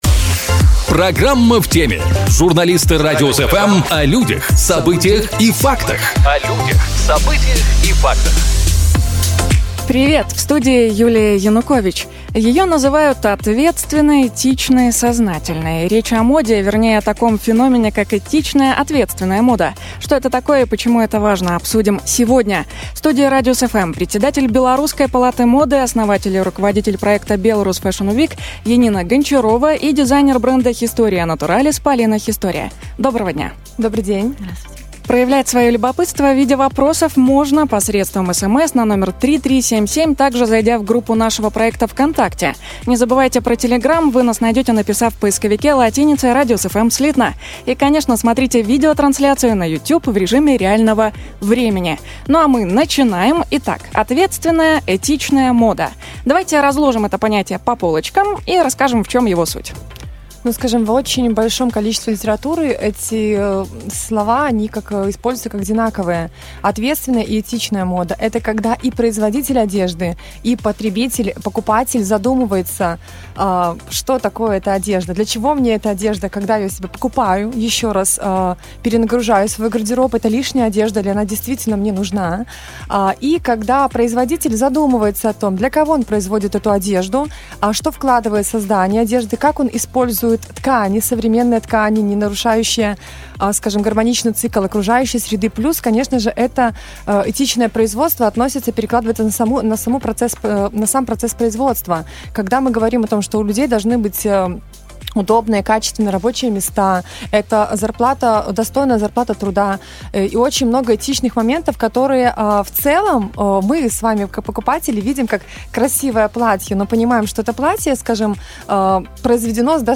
В студии "Радиус FМ"